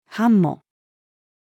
繁茂-female.mp3